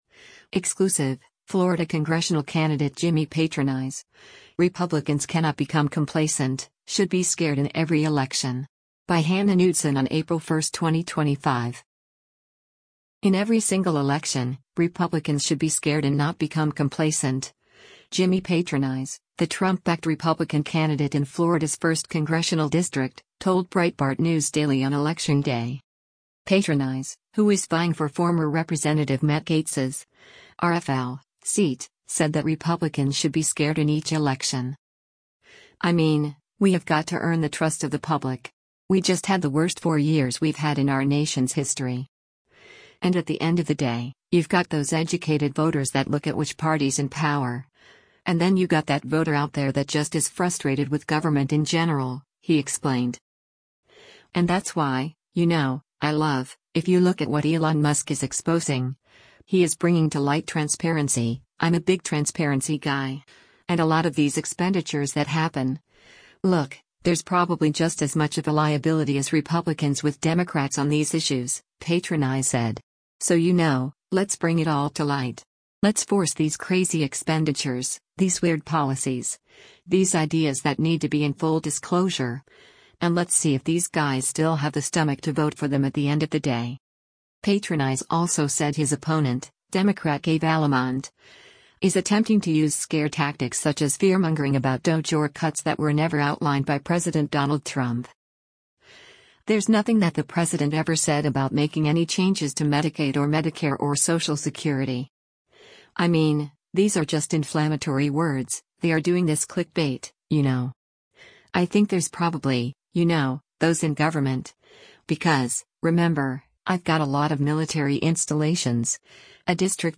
“In every single election, Republicans should be scared” and not become complacent, Jimmy Patronis, the Trump-backed Republican candidate in Florida’s 1st Congressional District, told Breitbart News Daily on election day.